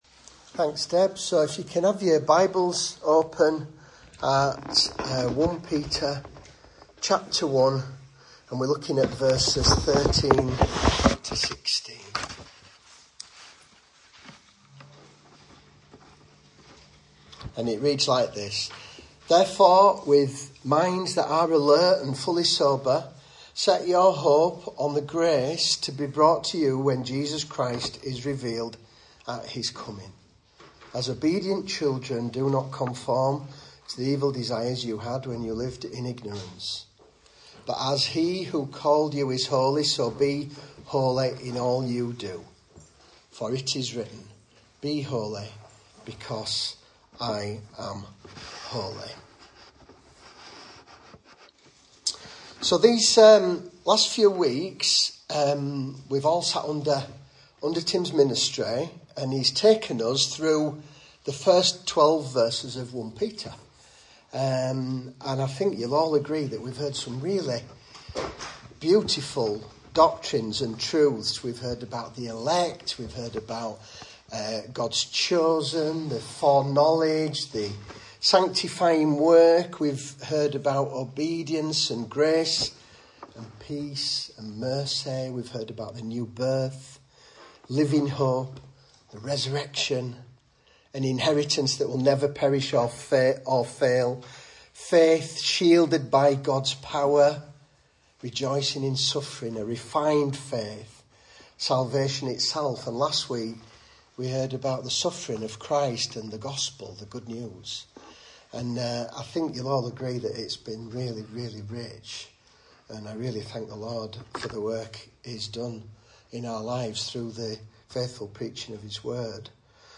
Message Scripture: 1 Peter 1:13-16 | Listen